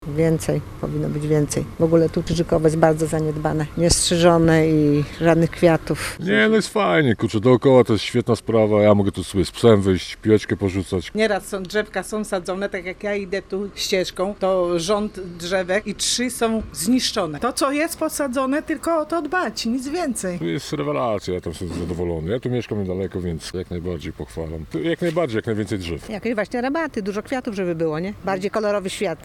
Co o tczewskiej zieleni mówią mieszkańcy?